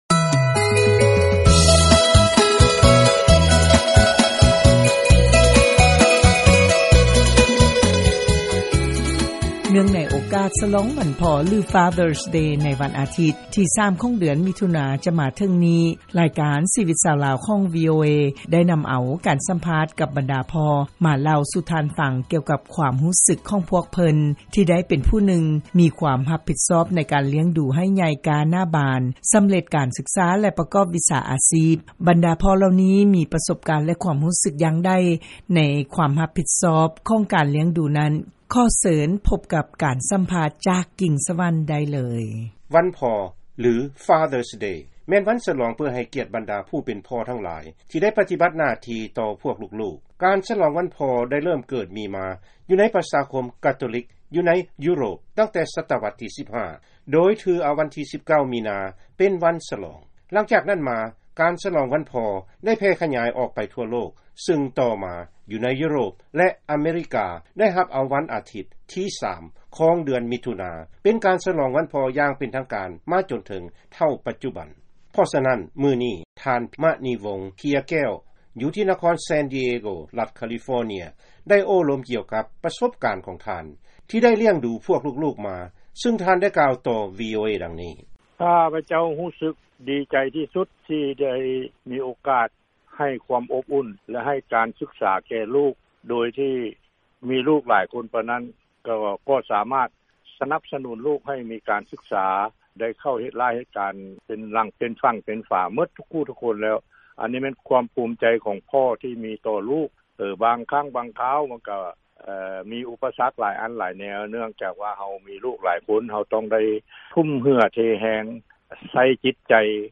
ເຊີນຟັງ ການສຳພາດ ໃນໂອກາດສະຫຼອງວັນພໍ່